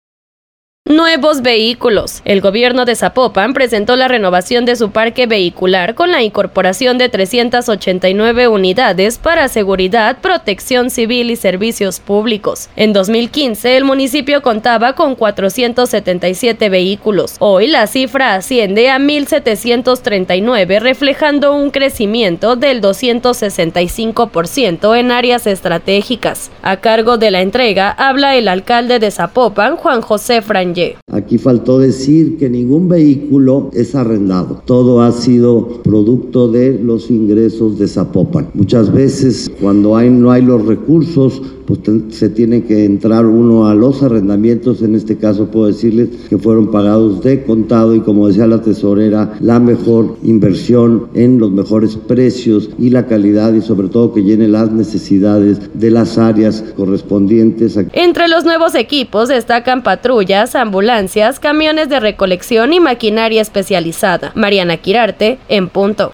A cargo de la entrega, habla el alcalde de Zapopan, Juan Jose Frangie.